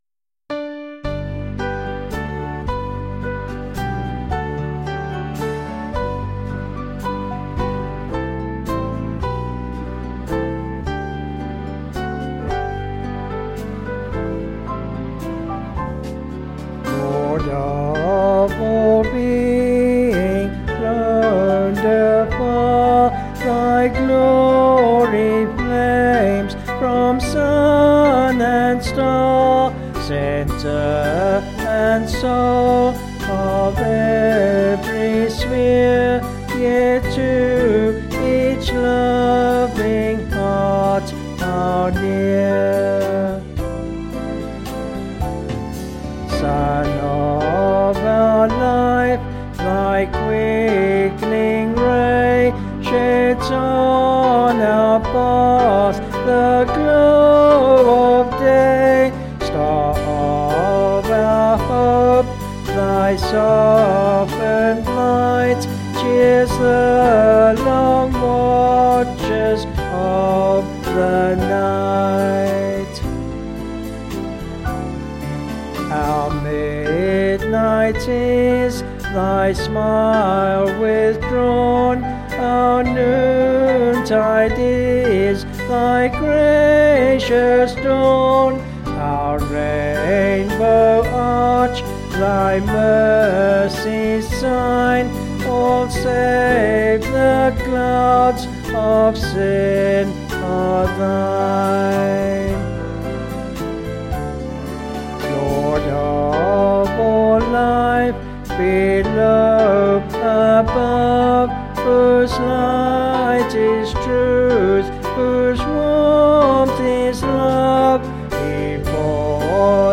264.4kb Sung Lyrics